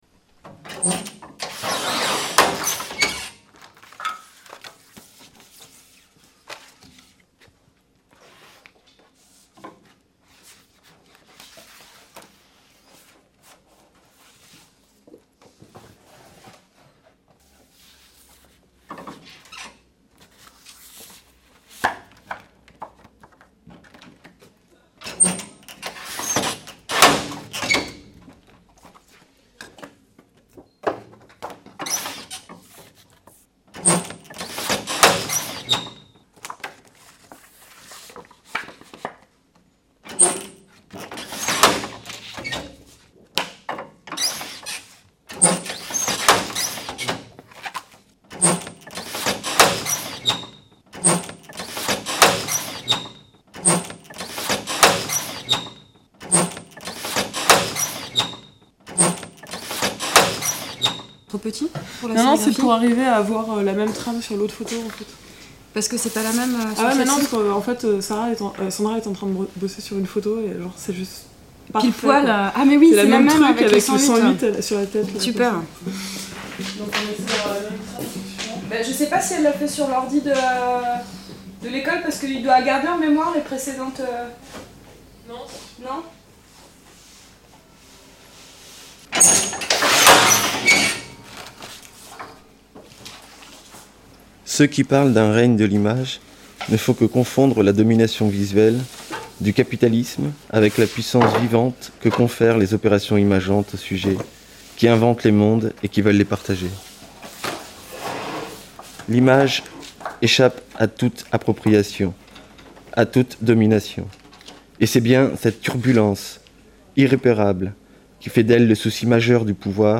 Lors du festival Bandits-Mages, un petit groupe d'�tudiants de l'Ecole Nationale Sup�rieure de Bourges ont r�alis� 4 fanzines en lien avec Radio 108. Ce document radiophonique en t�moigne.